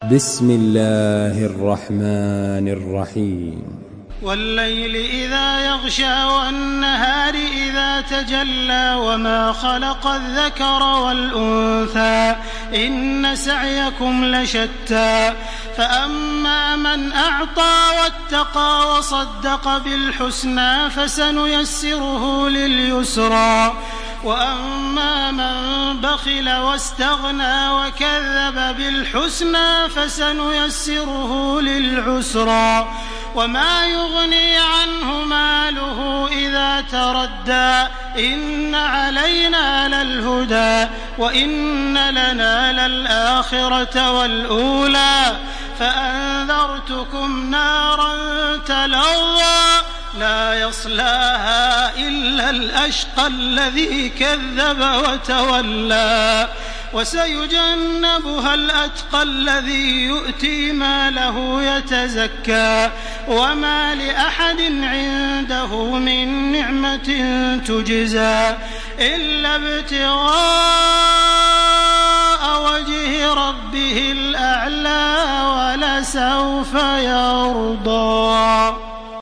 Surah الليل MP3 by تراويح الحرم المكي 1429 in حفص عن عاصم narration.
مرتل